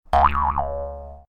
trampoline-cartoon-04.ogg